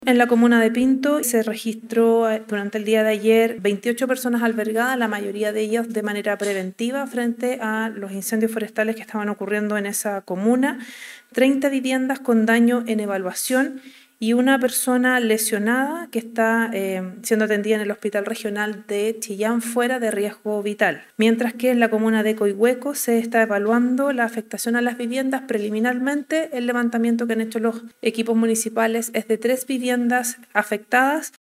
En relación con la afectación del territorio, la directora nacional de Senapred, Alicia Cebrián, confirmo que hubo personas albergadas durante la noche.